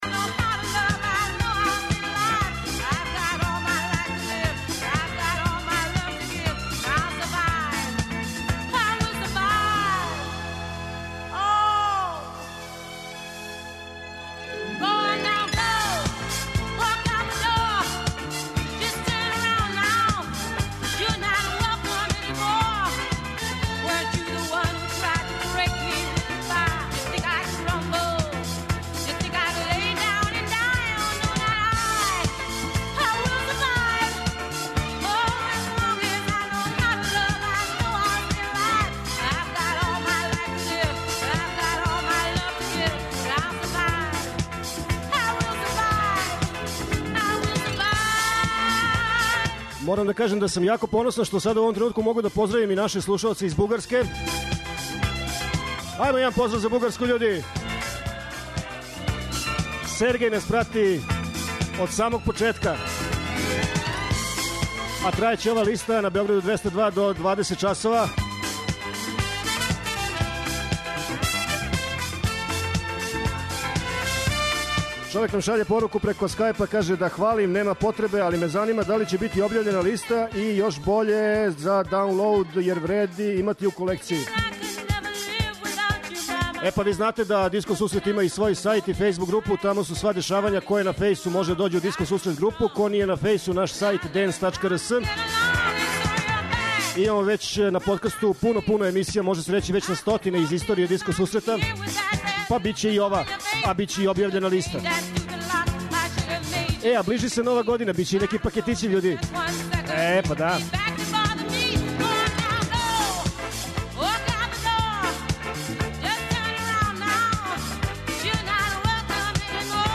Топ 100 листа се емитује из Witch Bara уз диркетан пренос на Београду 202.